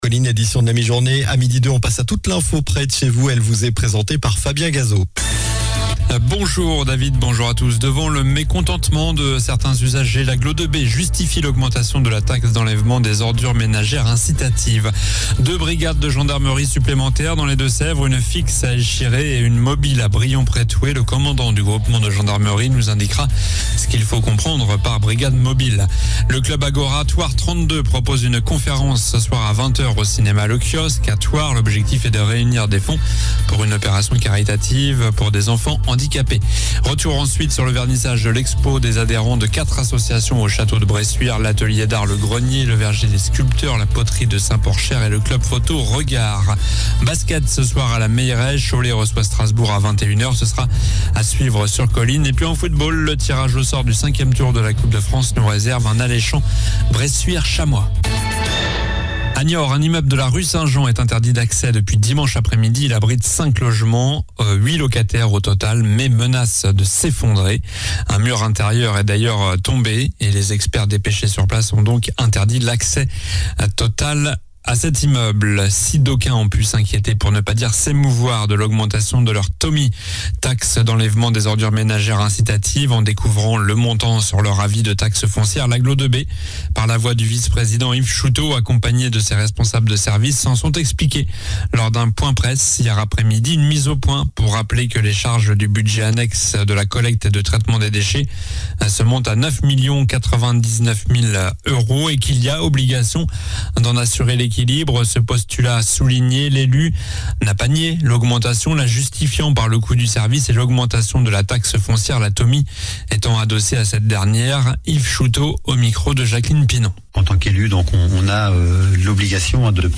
Journal du mardi 10 octobre (midi)